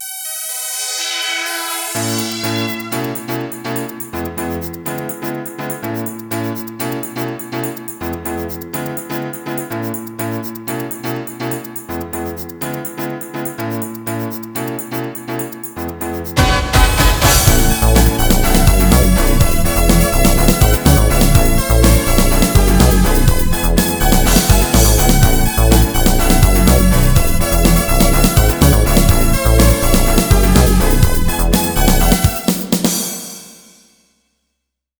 (Lower volume first on your speaker, it's loud!)
I like composing "Sweet, Cute, and Catchy" style music.